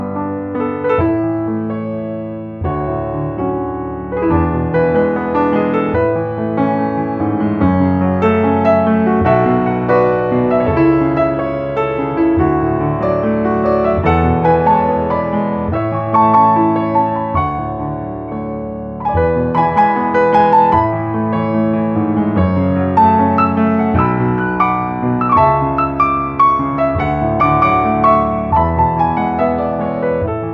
14 original, easy listening piano solos.